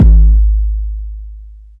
Waka KICK Edited (35).wav